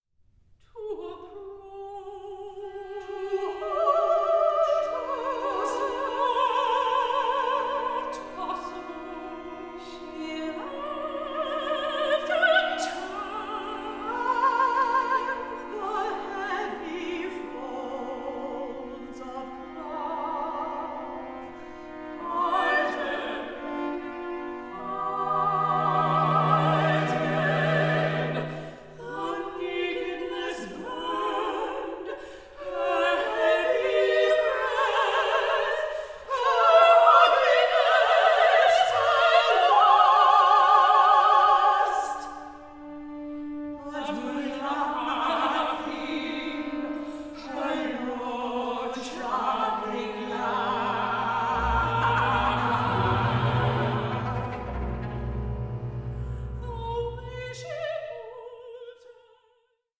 VOCAL WORKS WITH A PROFOUND HUMANITY